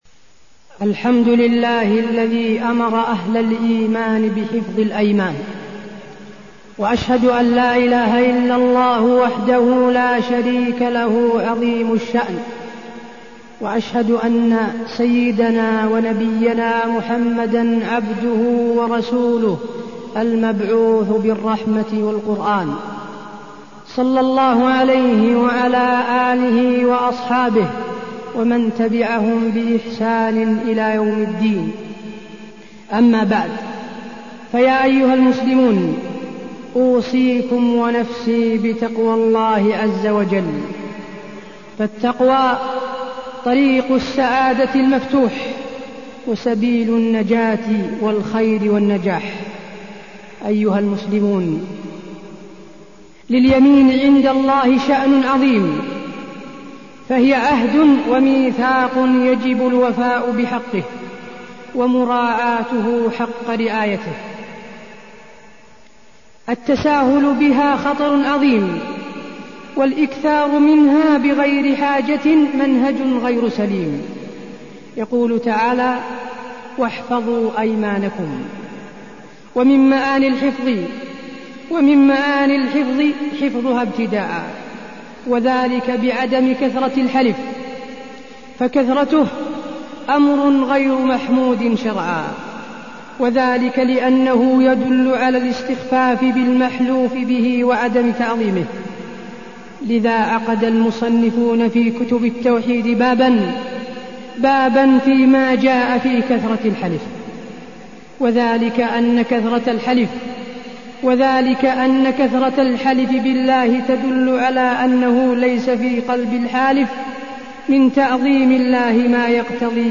تاريخ النشر ٢٤ ربيع الثاني ١٤٢٠ هـ المكان: المسجد النبوي الشيخ: فضيلة الشيخ د. حسين بن عبدالعزيز آل الشيخ فضيلة الشيخ د. حسين بن عبدالعزيز آل الشيخ حفظ الأيمان